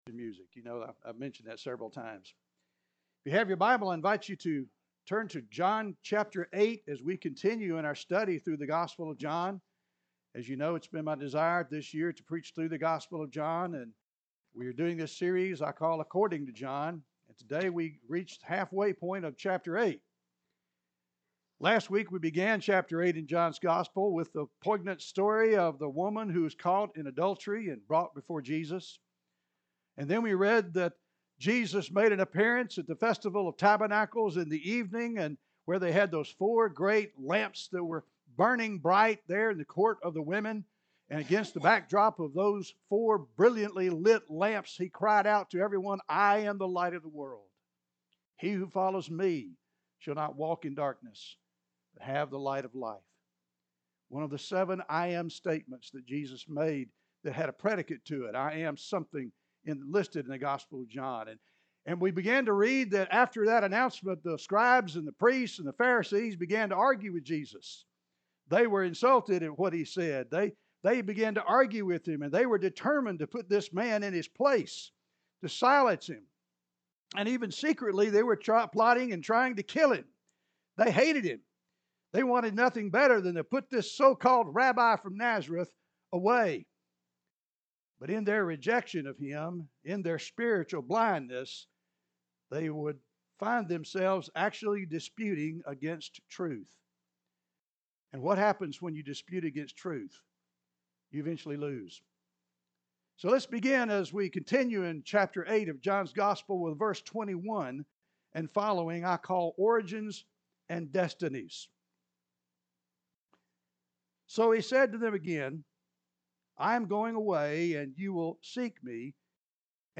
(Sermon series)